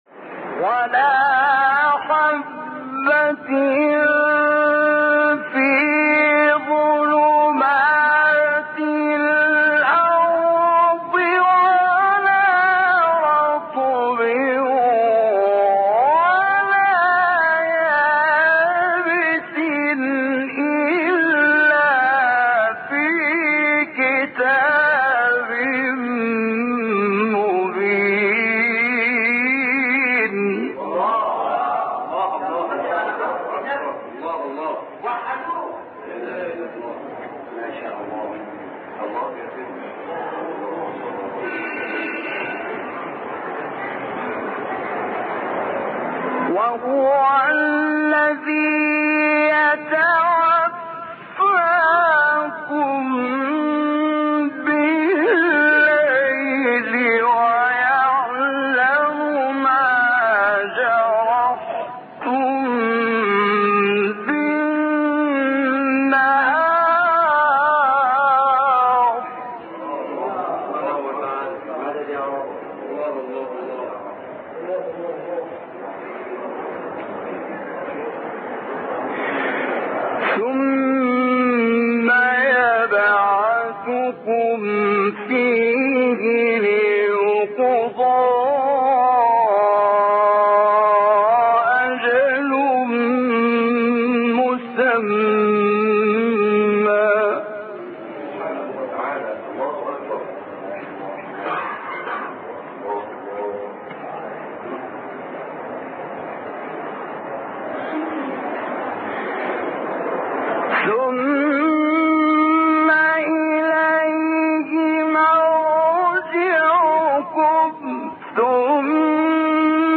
مقطع تلاوت سوره انعام 59-61
مقام : مرکب‌خوانی (رست * بیات)